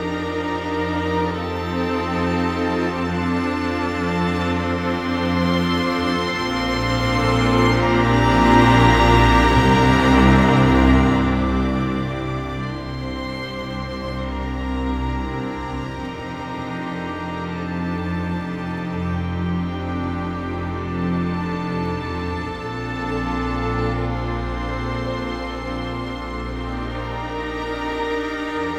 Rock-Pop 17 Strings 02.wav